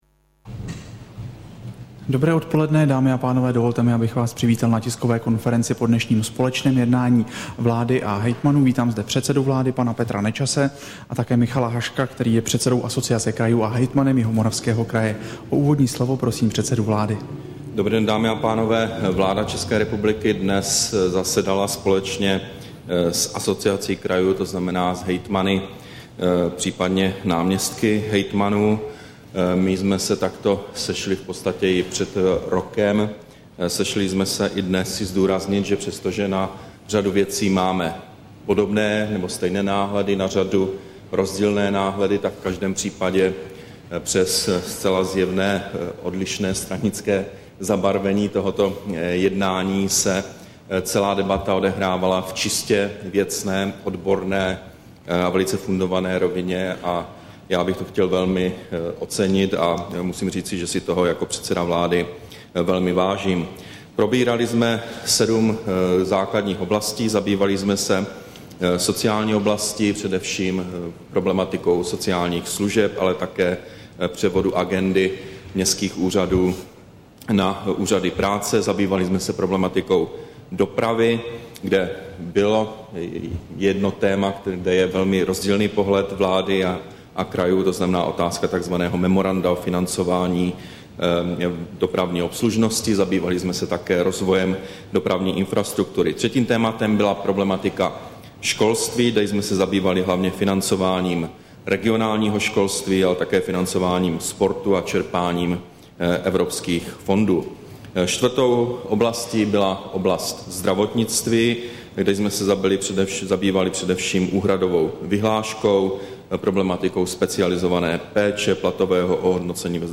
Tisková konference po jednání vlády s hejtmany, 26. srpna 2011